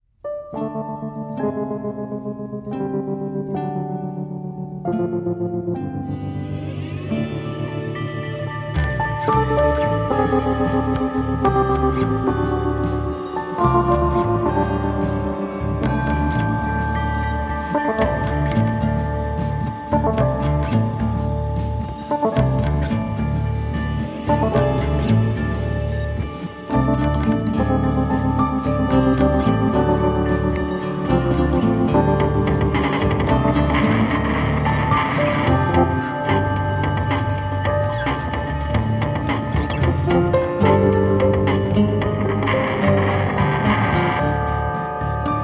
a cool, jazzy trip with a sci-fi chaser